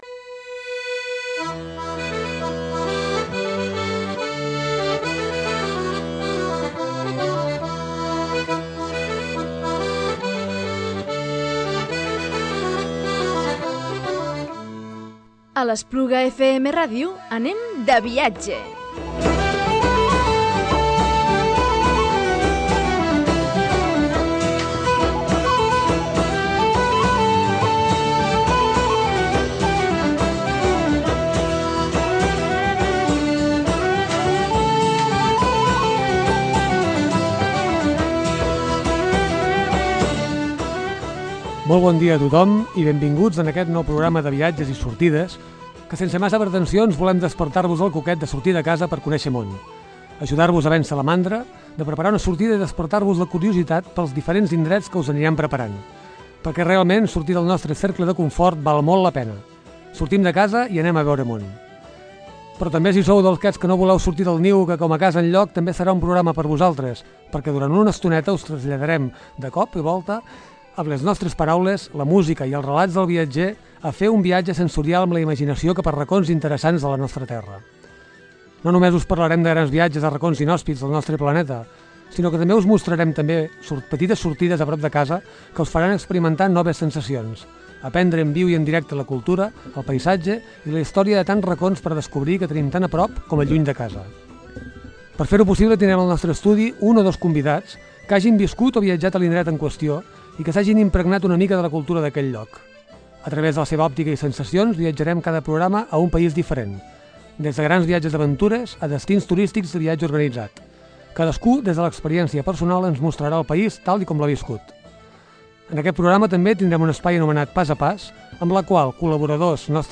En format de tertúlia, els dos convidats expliquen la seva experiència, que els va sorprendre i amb que es queden del viatge a Escòcia.